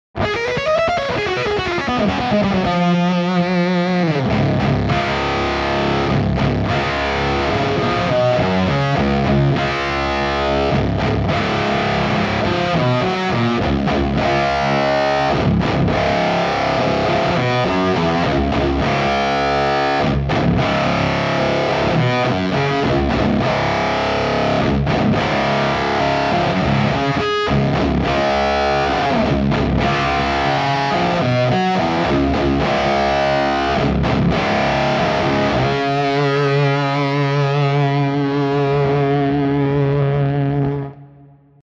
Bigger Wreck (fuzz then octave)
Bigger-Wreck.wav-fuzz-then-OCT.mp3